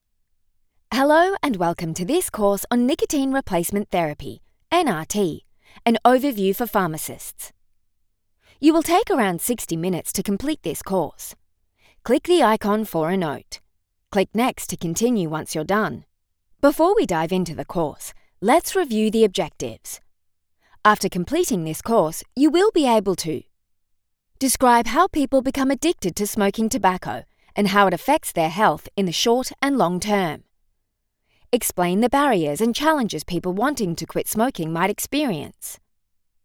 Natural, Travieso, Seguro, Amable, Cálida
Explicador